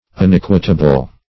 Unequitable \Un*eq"ui*ta*ble\, a.